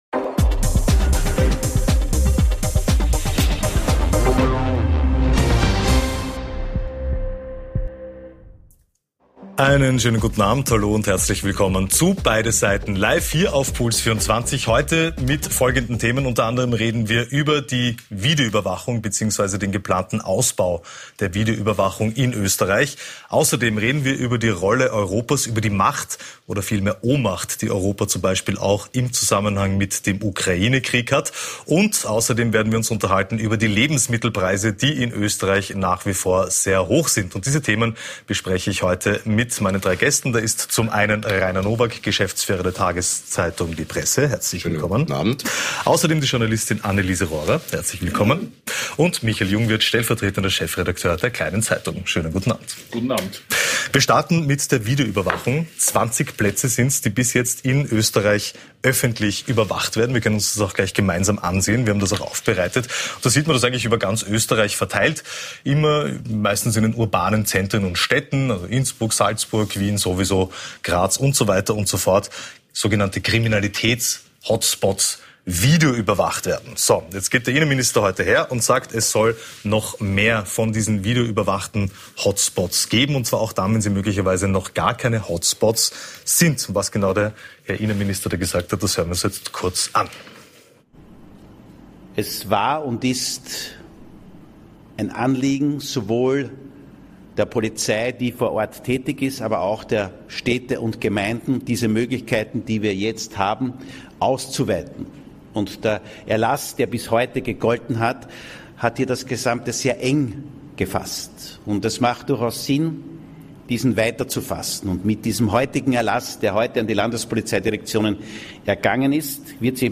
Wir beleuchten beide Seiten und präsentieren eine spannende Debatte.